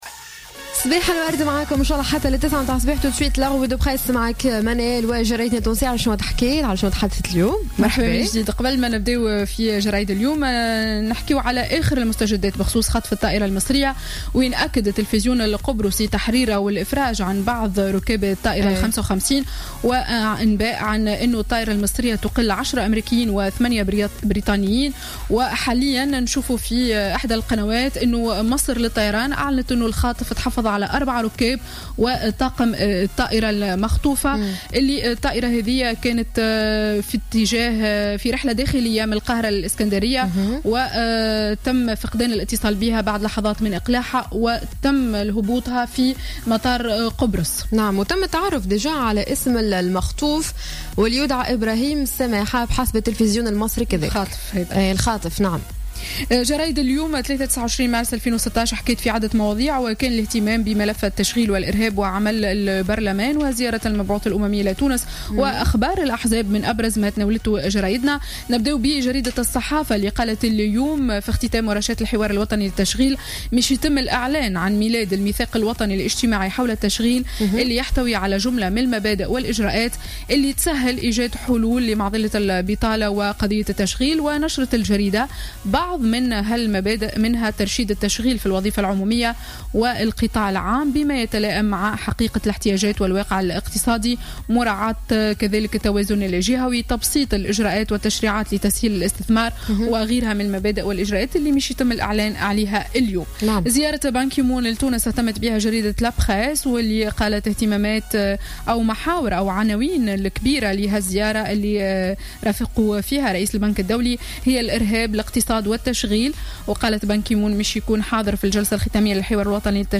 Revue de presse du mardi 29 mars 2016